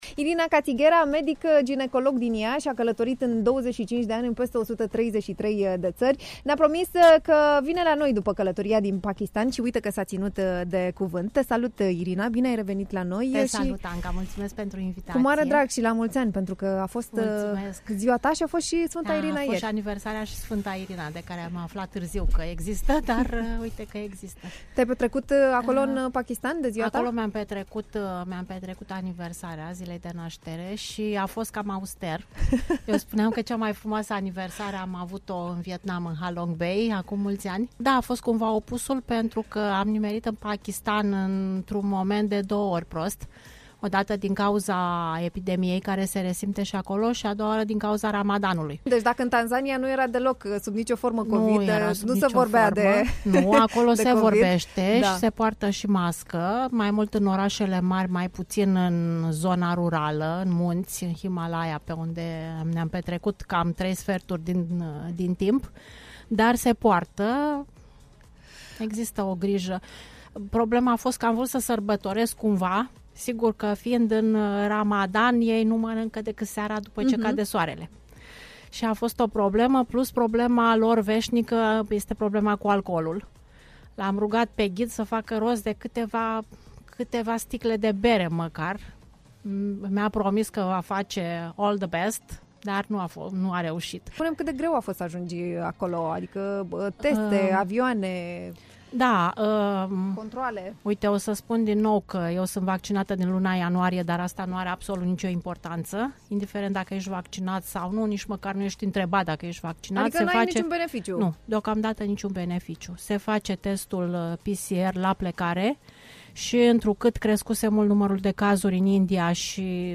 Cu ce impresii si experiențe s-a întors din Pakistan aflați în următorul interviu! https